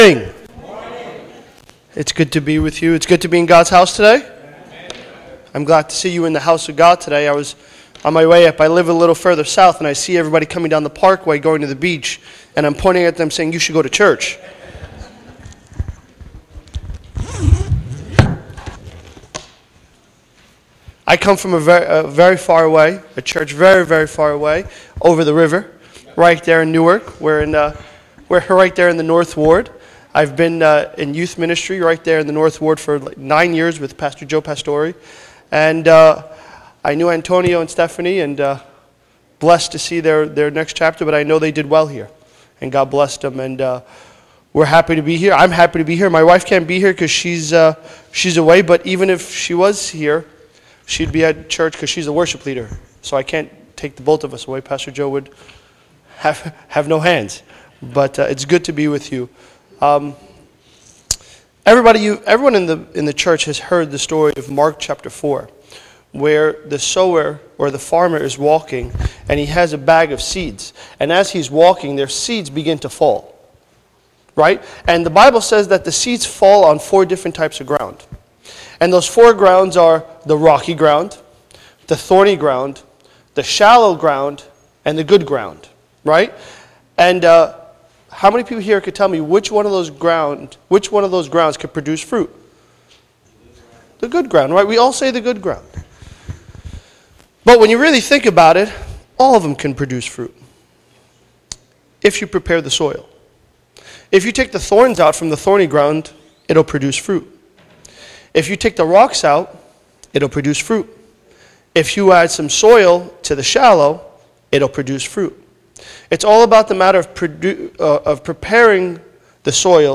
Invited speaker